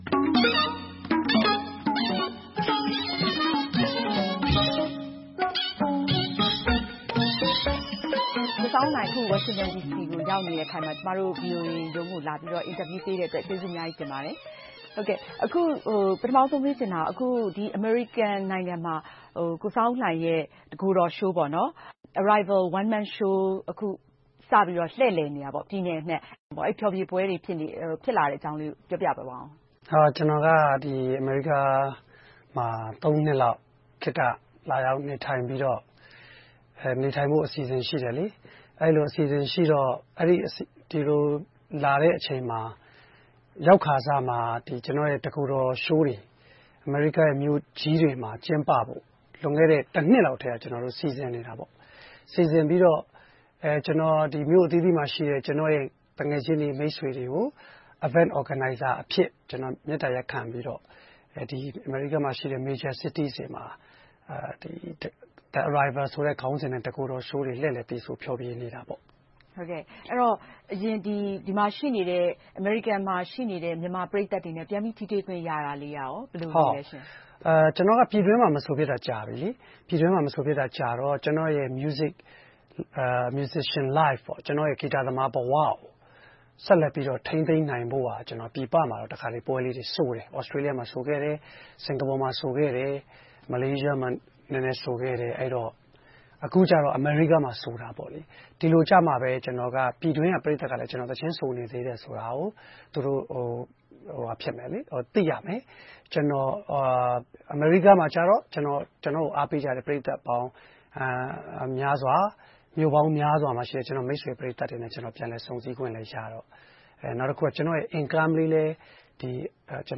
ဂီတအနုပညာရှင် ဆောင်းဦးလှိုင်နဲ့ တွေ့ဆုံမေးမြန်းခန်း